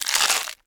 horror
Flesh Bite Crunch 2